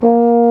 BRS BARI A#2.wav